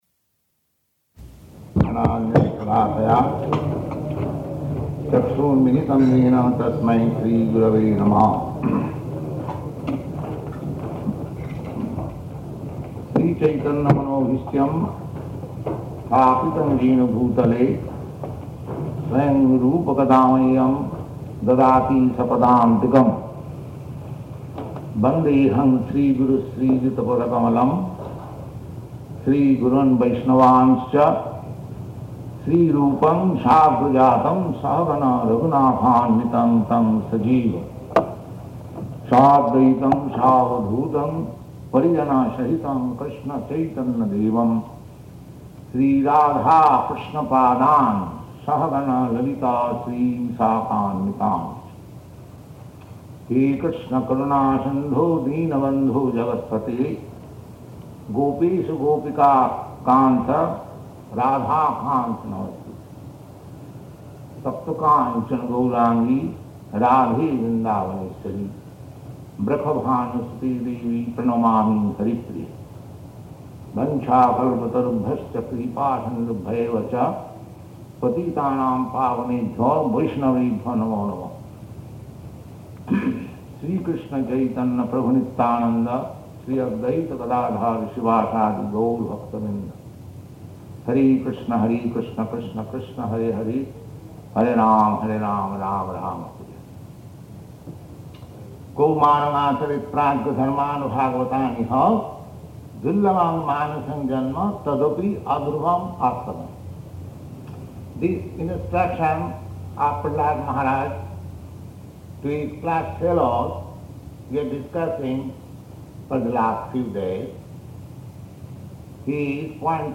Location: Montreal